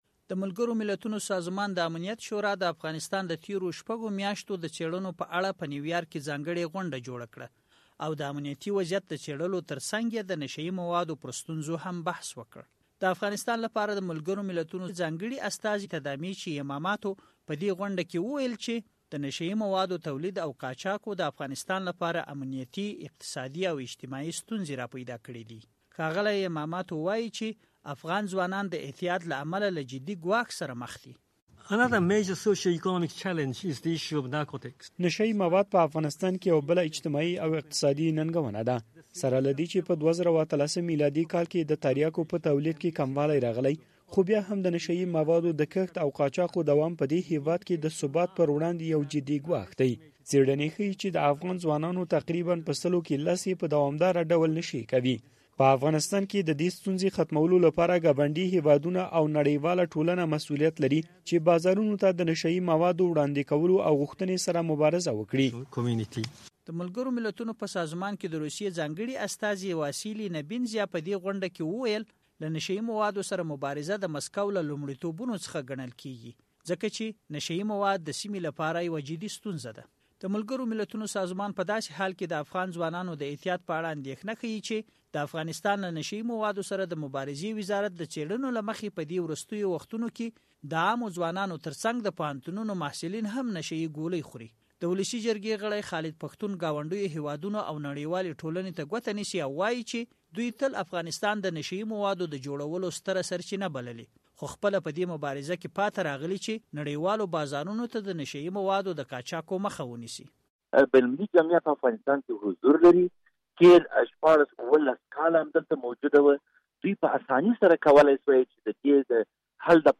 د زهرو کاروان غږیز راپور